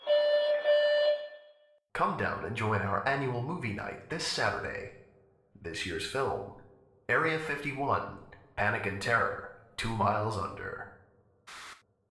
announcement7.ogg